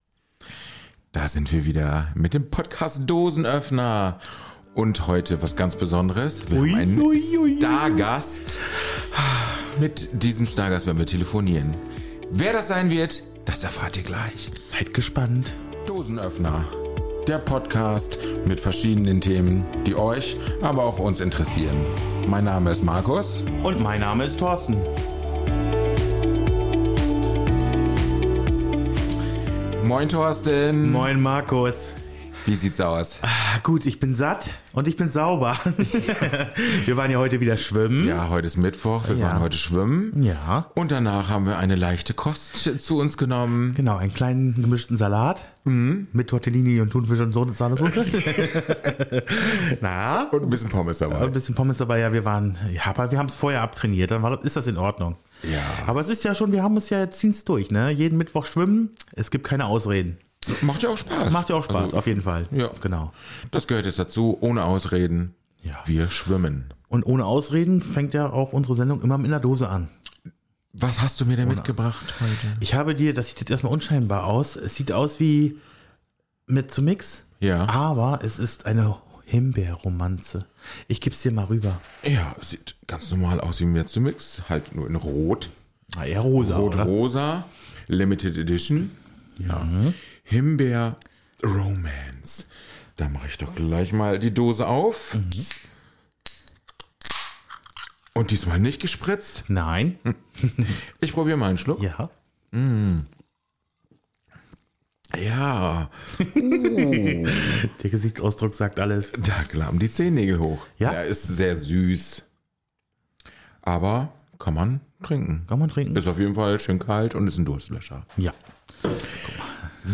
Lucy Diakovska im Dosenöffner Podcast Interview ~ Dosenöffner Podcast
Diese Folge kam spontan und wurde zu einem Gespräch, das uns wirklich berührt hat.